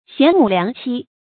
賢母良妻 注音： ㄒㄧㄢˊ ㄇㄨˇ ㄌㄧㄤˊ ㄑㄧ 讀音讀法： 意思解釋： 見「賢妻良母」。